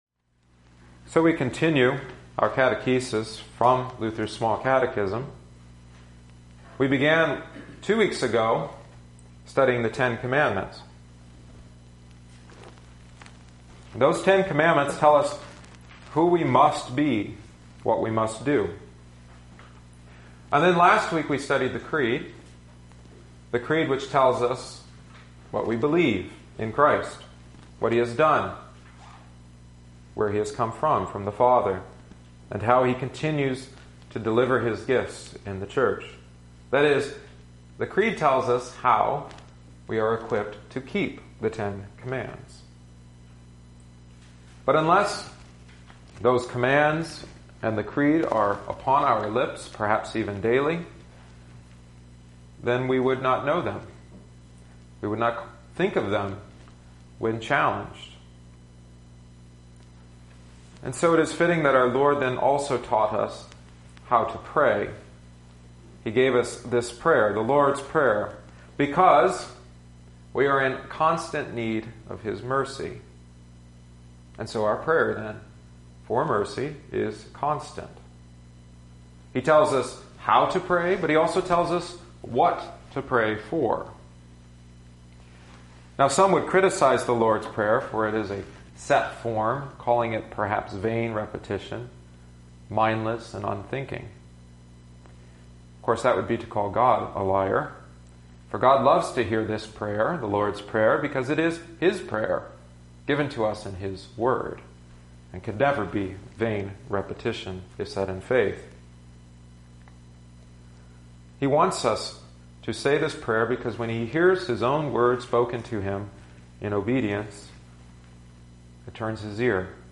Psalm 121 (antiphon: vv. 1-2) Office Hymn: 421 Jesus, Grant That Balm and Healing OT: 1 Kings 8:22-30; Ep: Eph 3:14-21; Gos: Mt 6:5-15 Catechetical Hymn: 766 Our Father, Who from Heaven Above